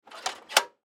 На этой странице собраны звуки прожектора: гул ламп, щелчки включения, фоновое жужжание.
Звук прожектора без передней крышки